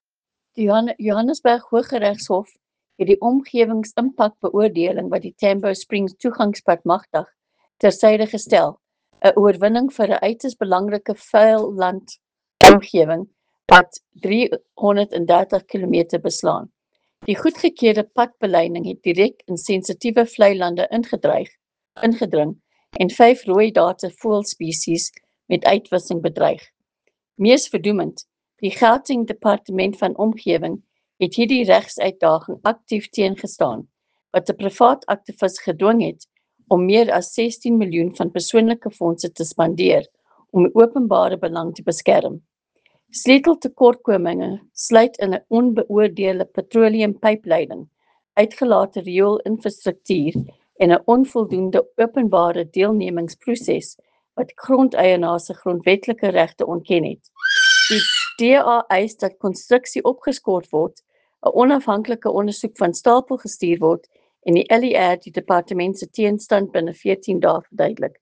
Note to Editors: Please find soundbites in
Afrikaans by DA MPL, Leanne De Jager MPL.